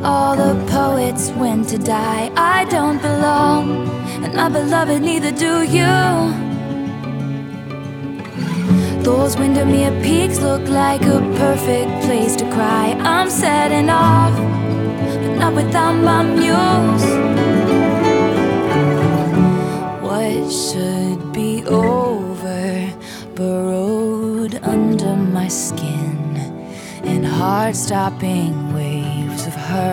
• Alternative
midtempo indie ballad, set to acoustic guitar and strings
and melancholic instrumentals